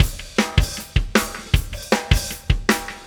Pulsar Beat 23.wav